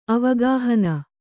♪ avagāhana